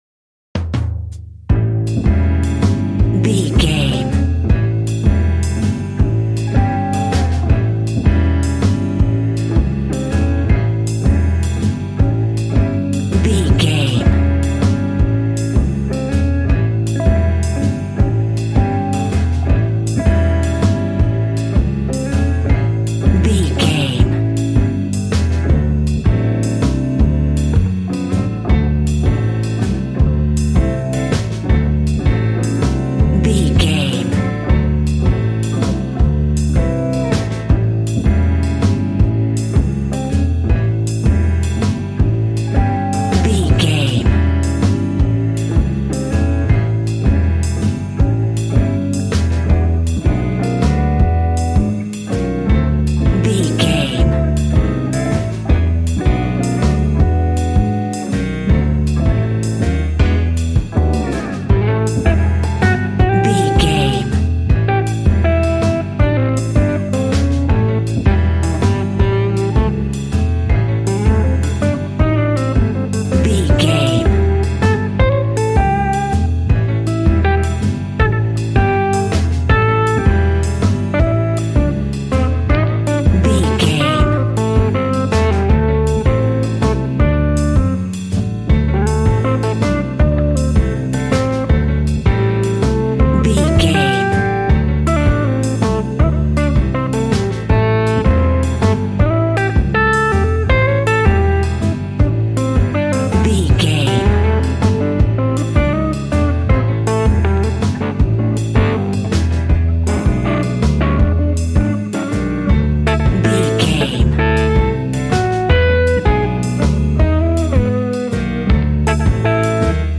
Ionian/Major
cheerful/happy
double bass
piano
drums